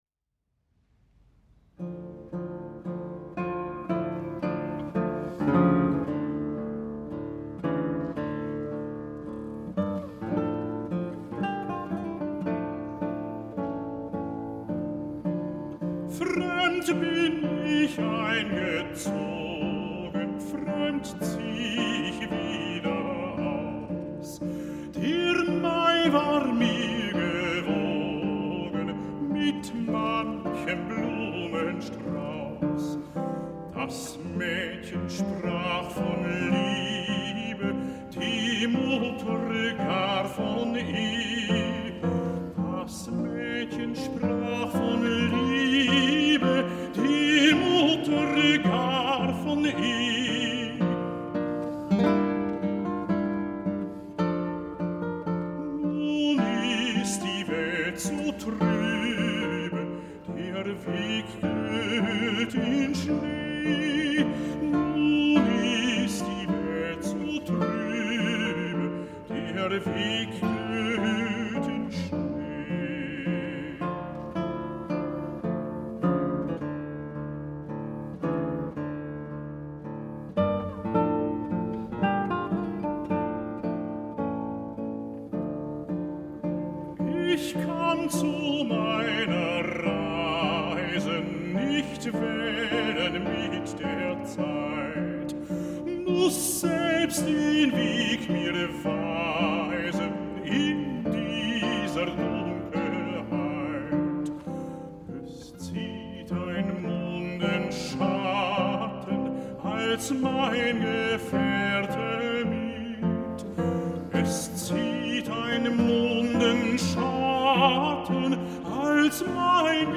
in concert
A really extraordinary live recording.“